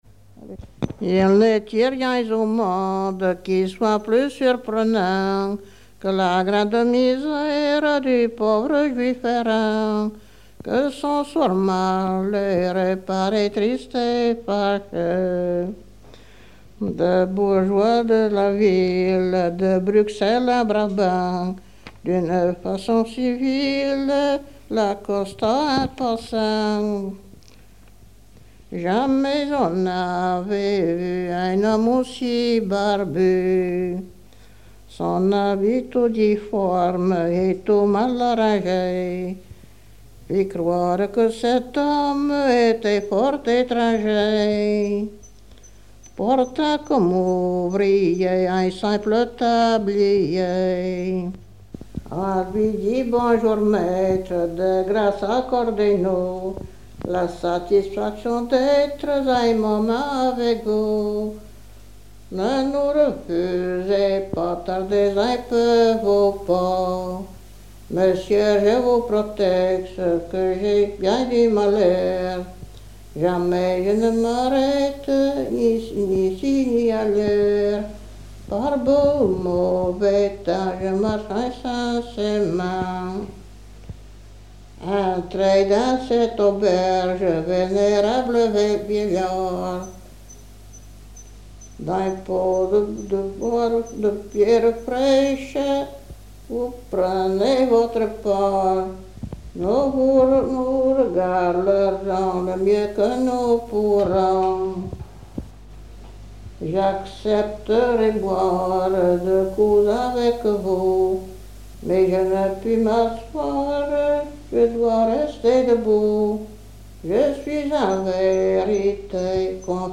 Saint-Jean-de-Monts
Chansons traditionnelles
Pièce musicale inédite